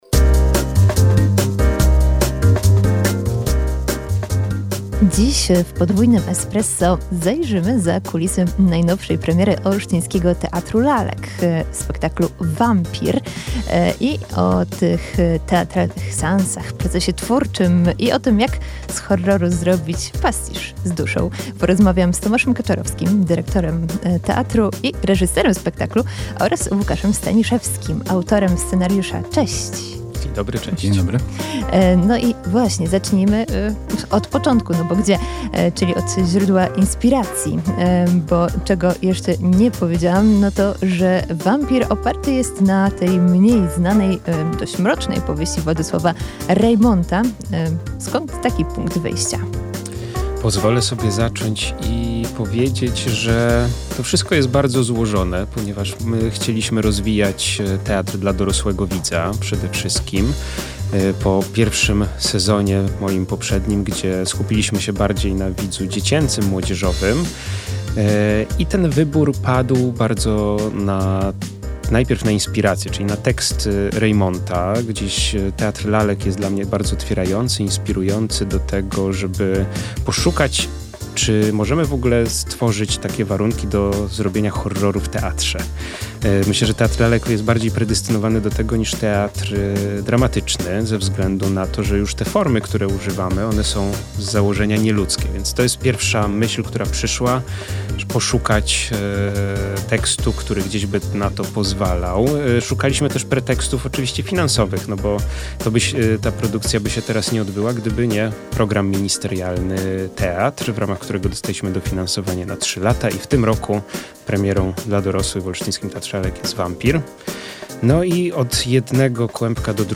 w studiu Radia UWM FM zdradzili, jak powstaje ten niezwykły projekt dla dorosłych widzów.